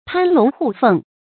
發音讀音
成語拼音 pān lóng xiù fèng